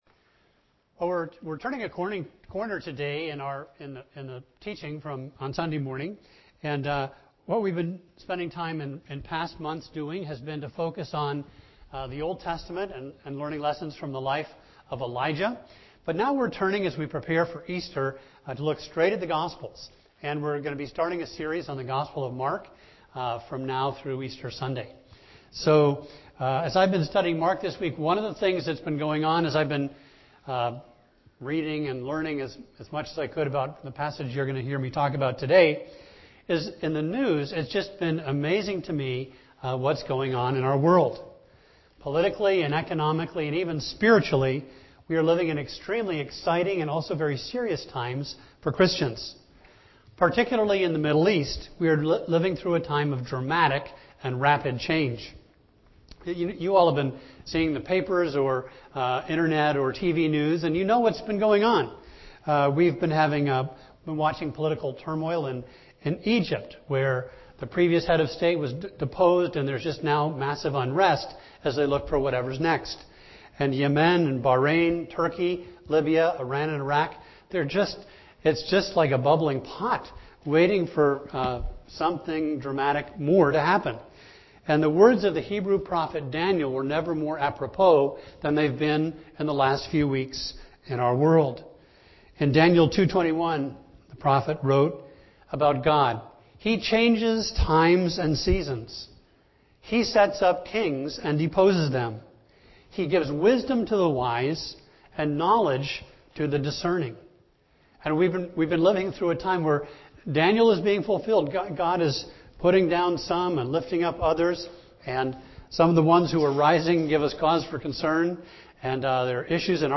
A message from the series "Gospel of Mark."